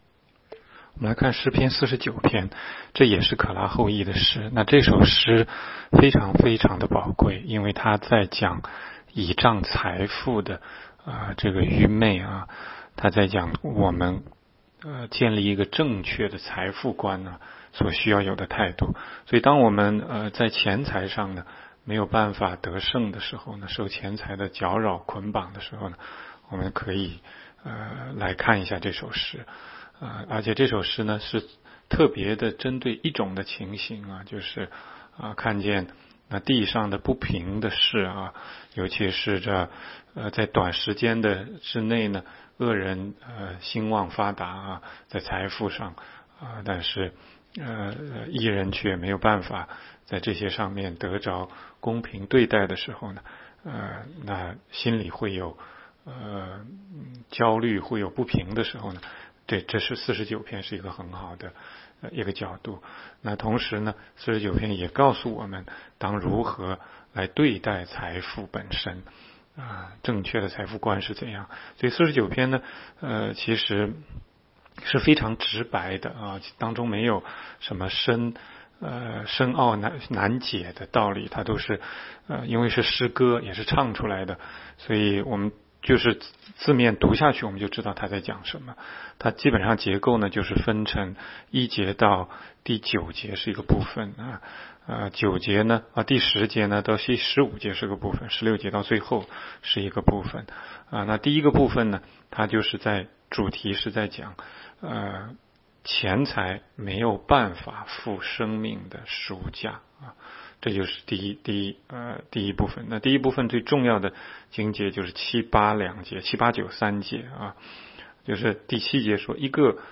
16街讲道录音 - 每日读经-《诗篇》49章
每日读经